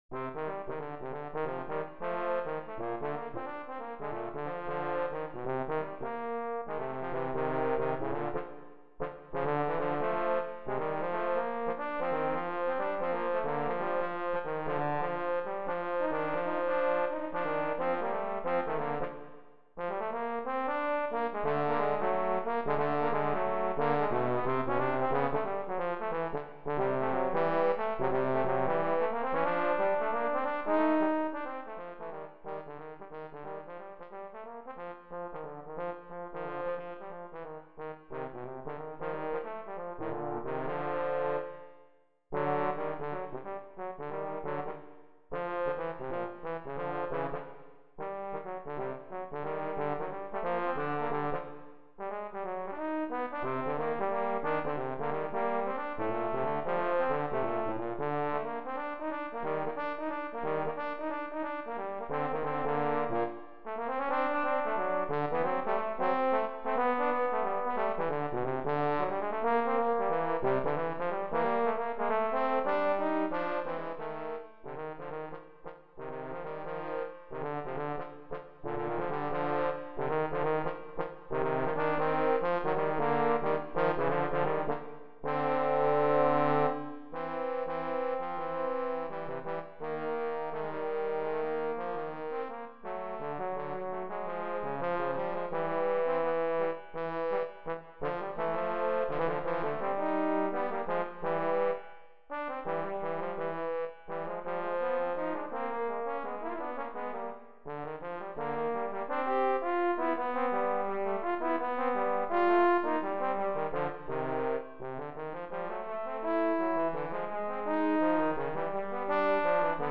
Gattung: Für 2 Posaunen